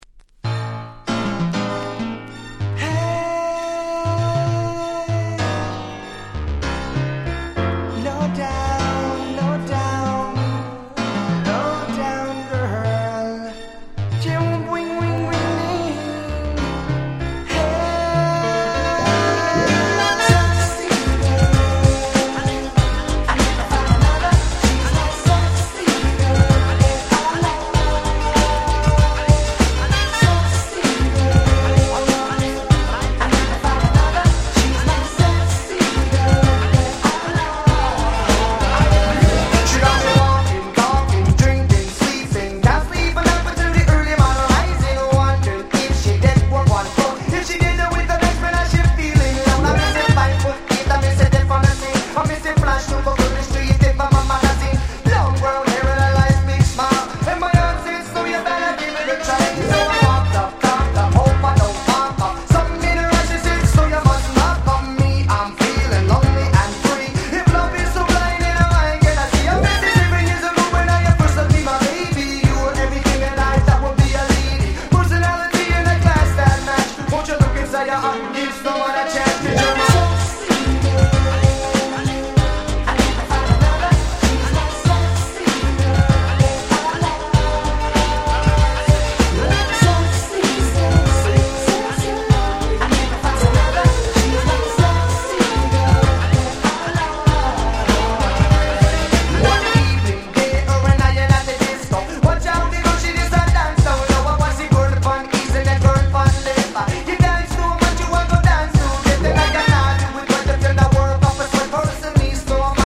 このコンピの特徴は「音が良い」事。
夏にぴったりなPop Reggaeヒット4曲収録で超お得！